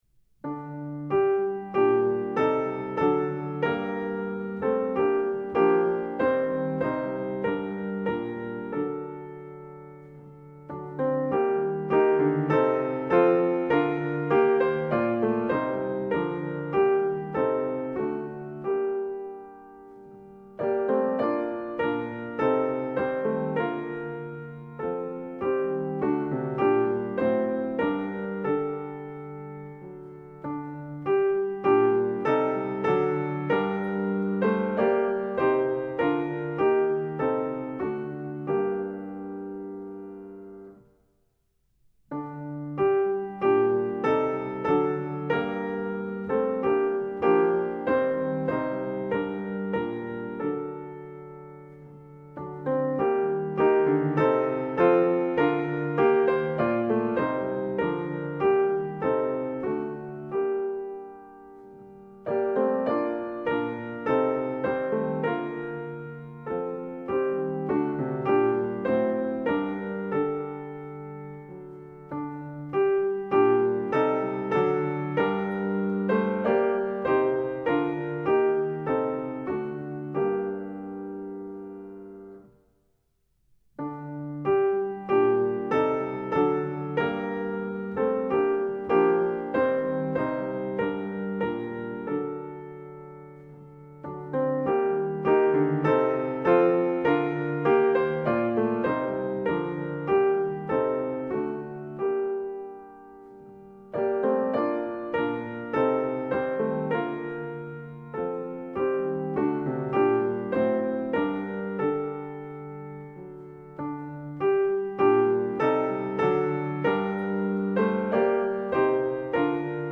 Practice singing the hymns for this Sunday’s worship services using the sheet music and audio accompaniment below.
Sing with accompaniment (plays through all verses, no intro):